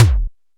Index of /90_sSampleCDs/Roland - Rhythm Section/DRM_Analog Drums/KIK_Analog Kicks